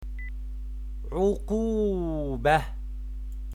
This lesson is for students who interests in learning Arabic Language for practicing politics or international press in Arabic . it contains 30 of the most common words in the field of The political field in Arabic. you can read and listen the pronunciation of each word .